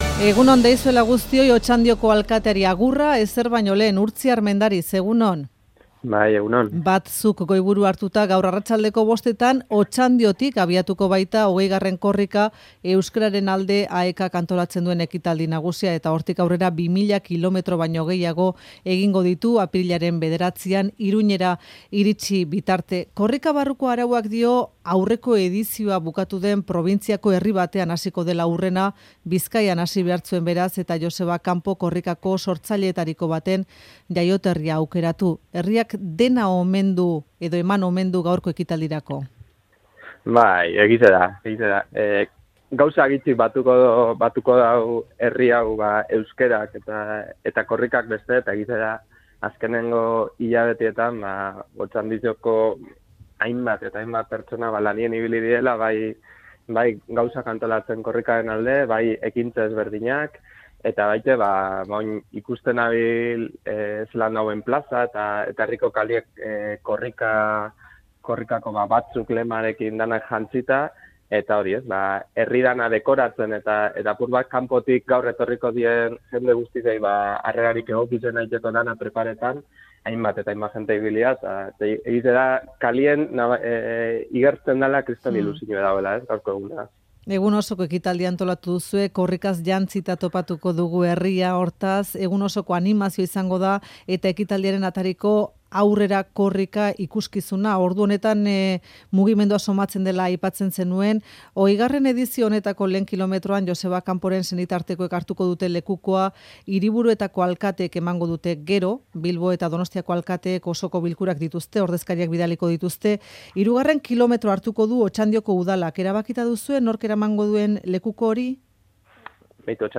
Urtzi Armendariz, Otxandioko alkatea,Euskadi Irratiko Faktorian